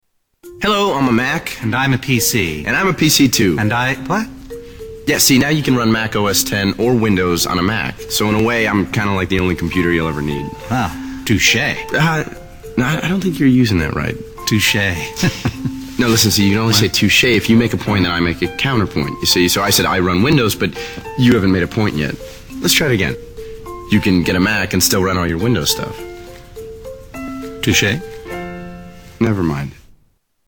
Tags: Media Apple Mac Guy Vs. PC Guy Commercial Justin Long John Hodgeman